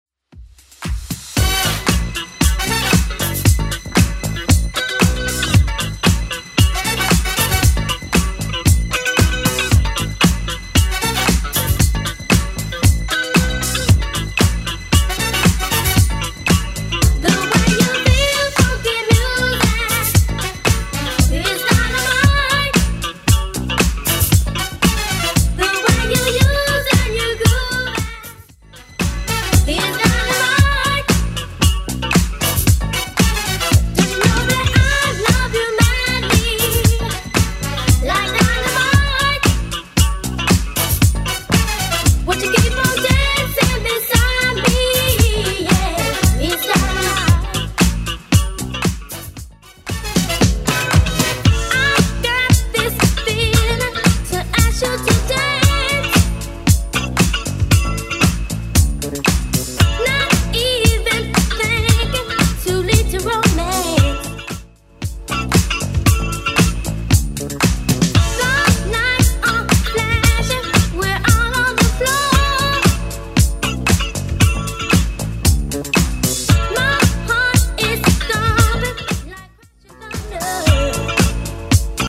Genre: 80's
BPM: 118